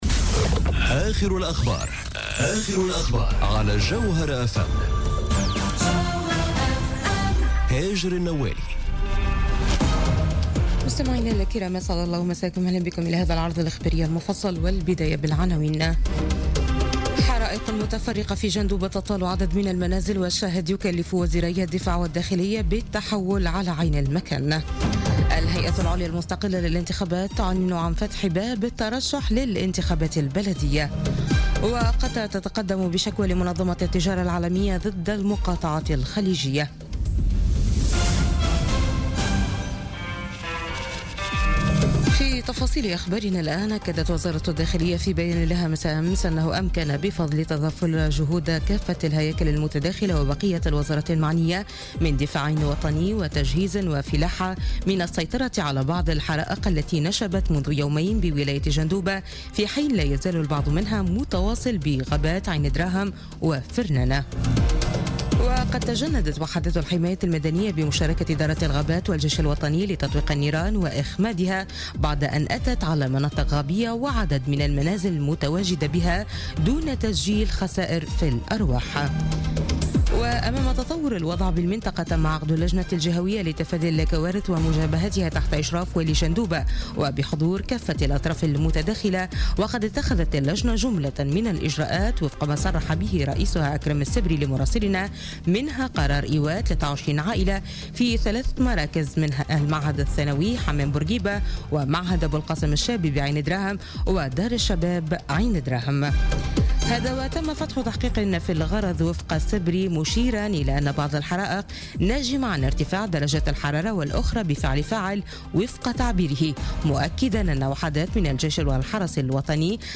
نشرة أخبار منتصف الليل ليوم الثلاثاء غرة أوت 2017